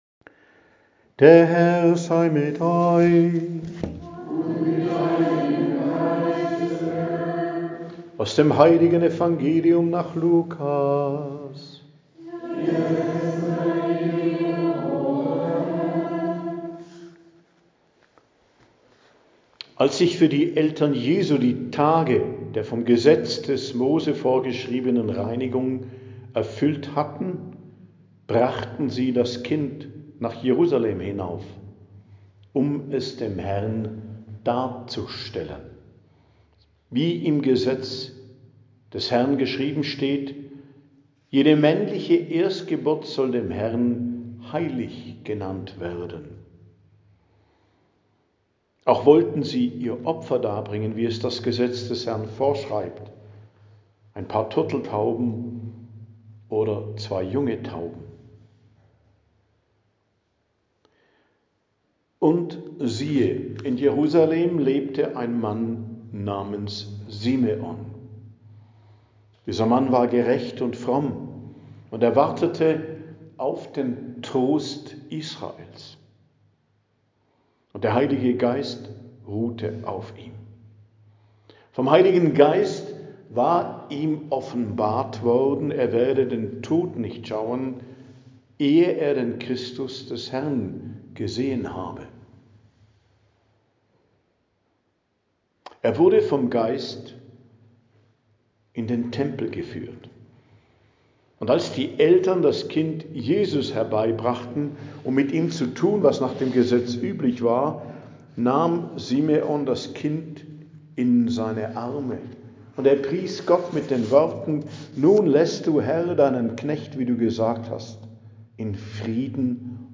Predigt am Fest Darstellung des Herrn, 2.02.2026 ~ Geistliches Zentrum Kloster Heiligkreuztal Podcast